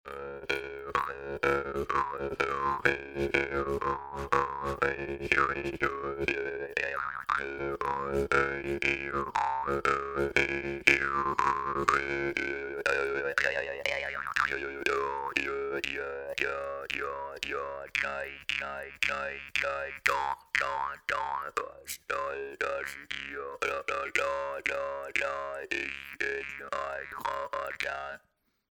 Guimarde: A drone instrument found across the globe in various constructions.
Maultrommel.mp3